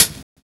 Snare set 2 012.wav